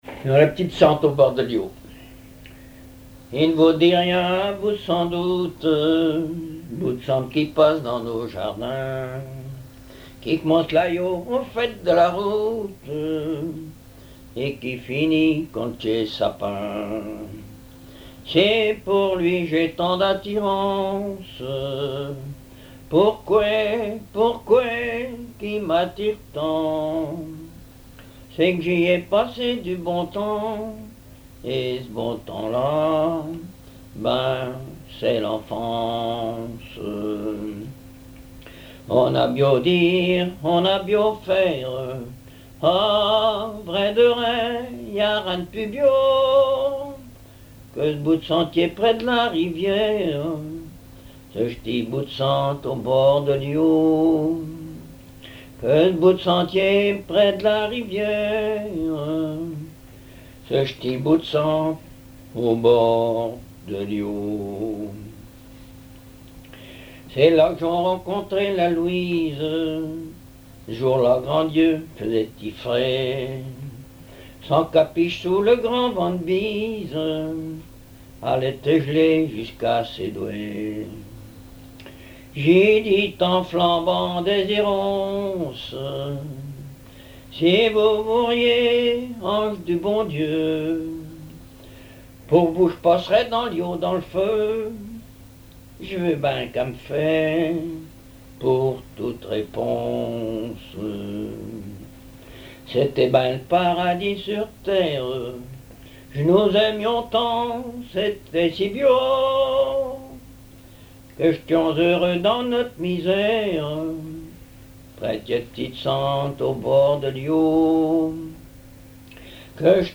Patois local
Genre strophique
contes, récits et chansons populaires
Pièce musicale inédite